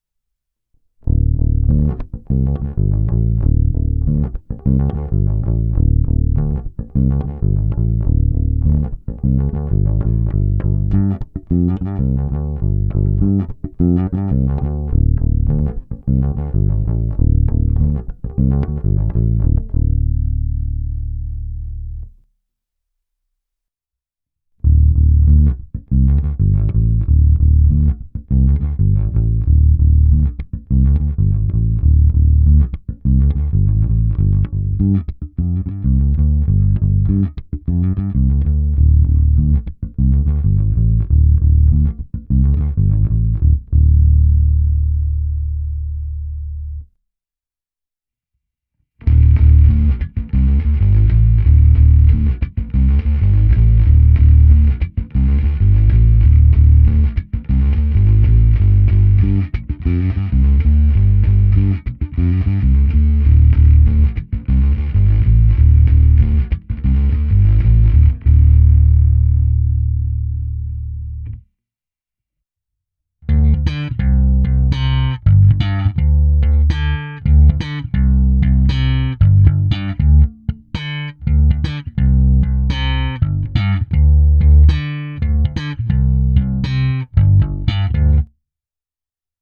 Já nahrál jen moje předvolby, tak, jak bych je použil.
První část je zvuk samotné baskytary rovnou do zvukové karty, následuje ukázka čistého zvuku přes Photon se simulací aparátu a kompresorem, pak ukázka zkreslení a nakonec ukázka slapu. I na ten jsem si udělal speciální preset se speciálně nastaveným kompresorem a ekvalizační sekcí stylem mid-scoop a dokonce i s jinou simulací než o ostatních dvou předvoleb.